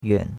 yuan3.mp3